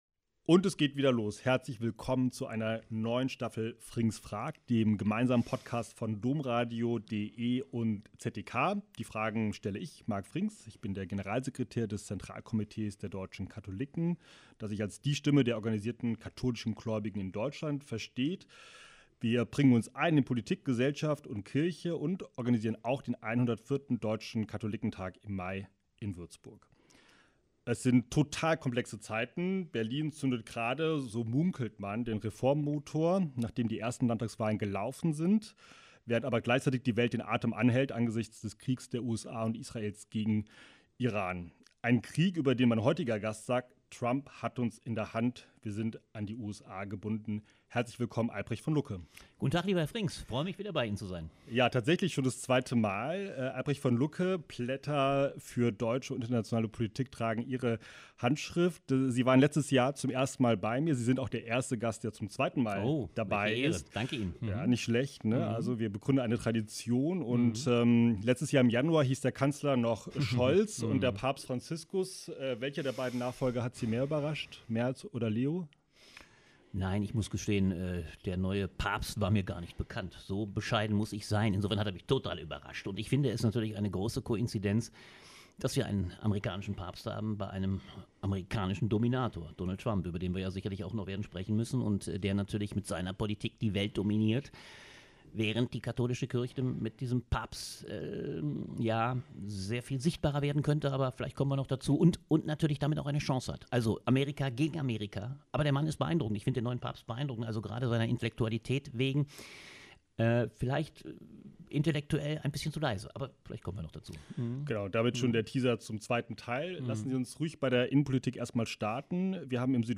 Nachdenklich, unterhaltsam, mit klarer Meinung: